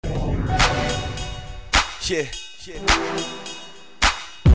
Помогите пожалуйста создать вот такой звук, похожий на pad или струнные с медленной атакой. Или хотябы снять эти 2 аккорда